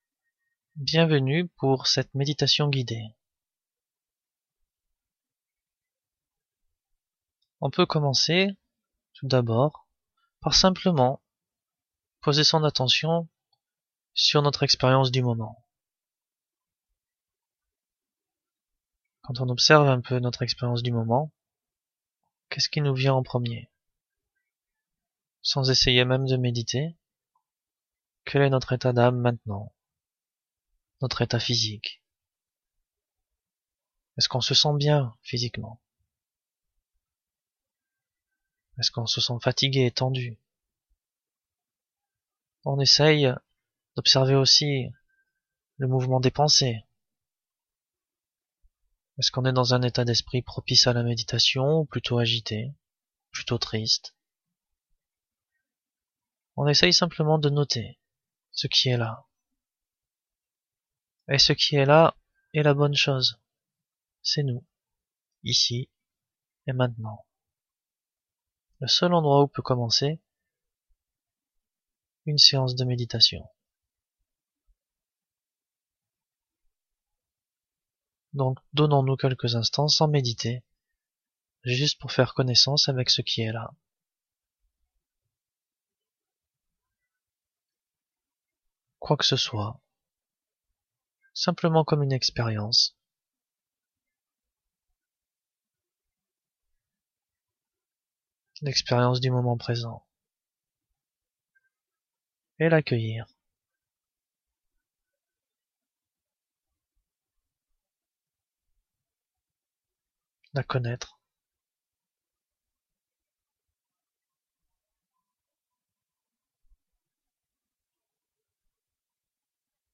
Méditation assise